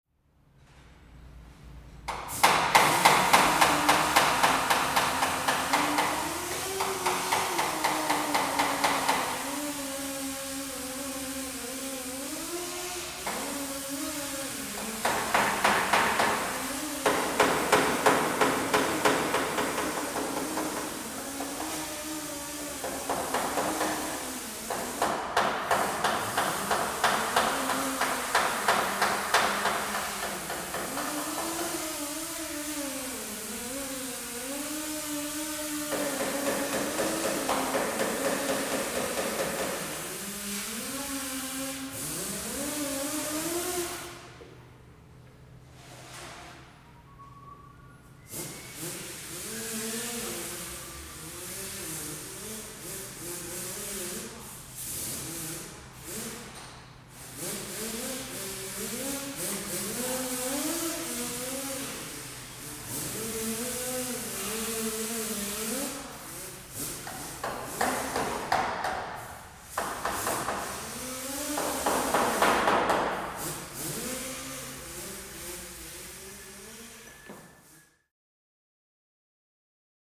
Auto Shop
Automobile Body Shop Ambience, W Pneumatic Tools And Hammering Dents.